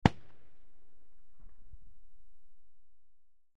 Ak-21 Machine Gun Single Shot From Distant Point of View, X4